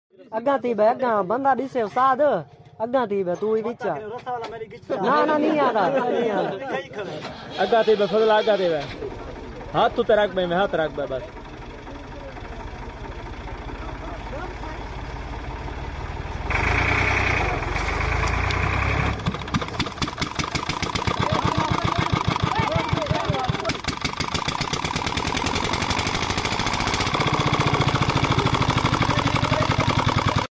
Petter Diesel Engine Starting Up Sound Effects Free Download
petter diesel Engine Starting Up